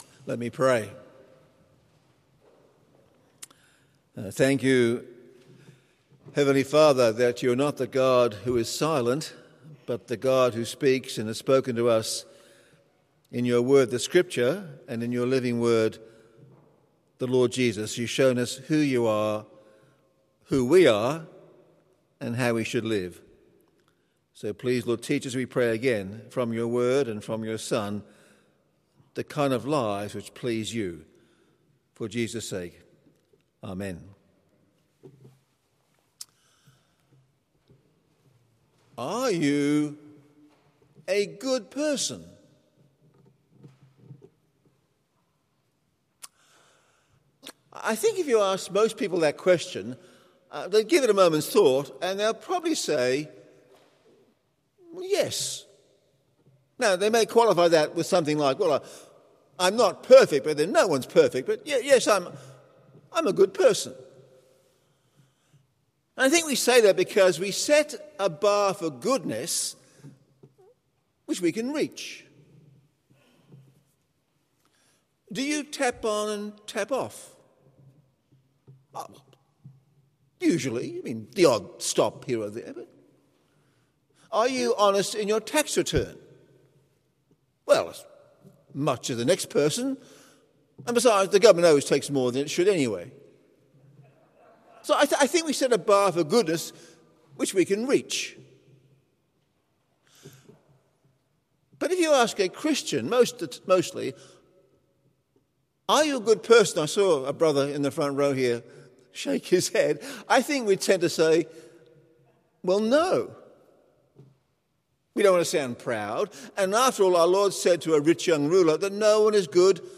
Full Service Audio
The Scots’ Church Melbourne 11am Service 24th of January 2021